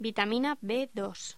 Locución: Vitamina B2
voz